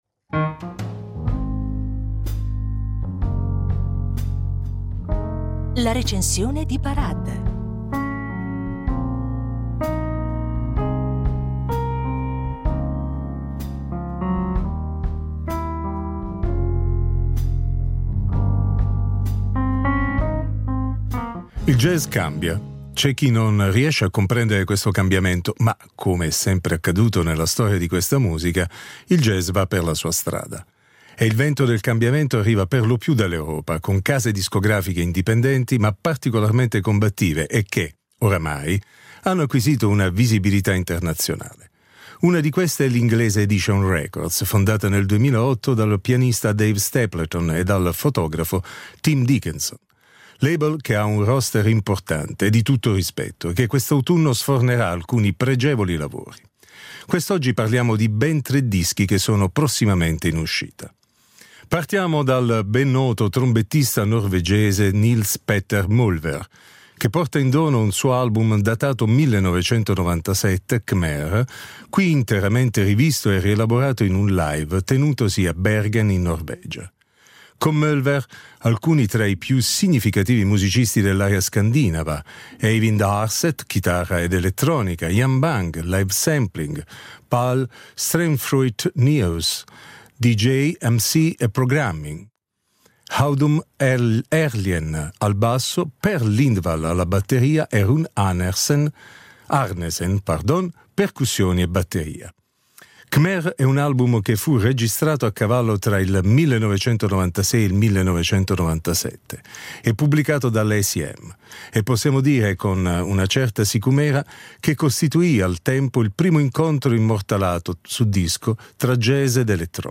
Tra improvvisazione ed elettronica